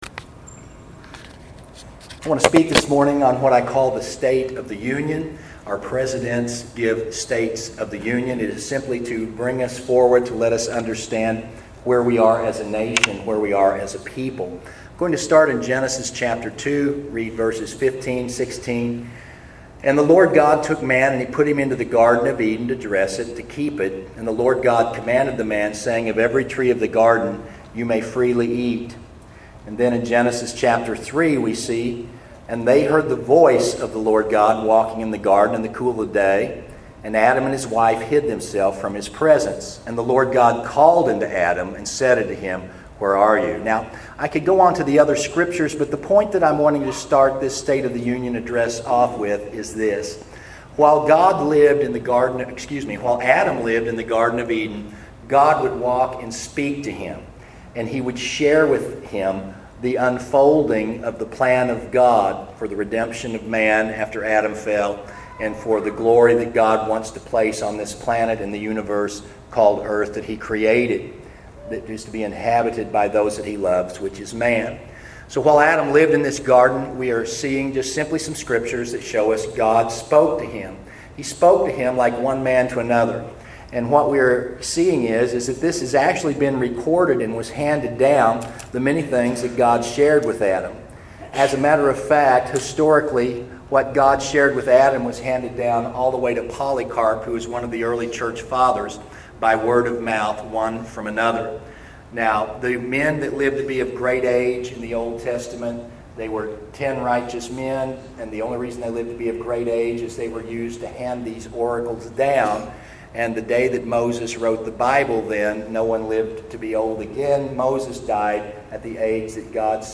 Many statistics are given in this lecture, as well as historical facts, Supreme Court rulings, and current changes in our laws.